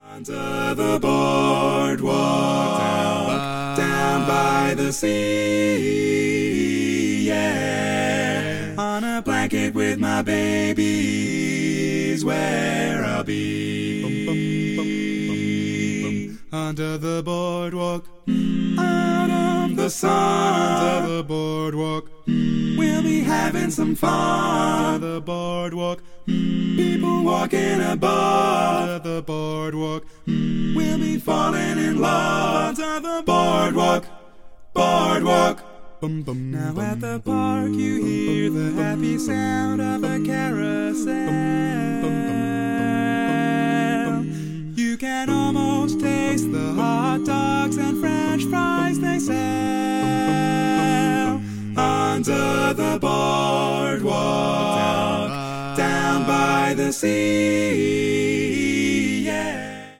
Male